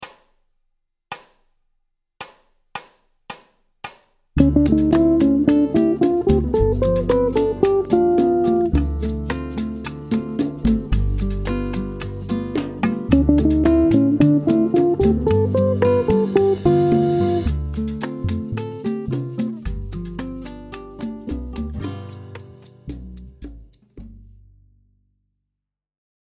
Sur l’accord de dominante (G7) descente du mode de D dorien à partir de la Septième mineure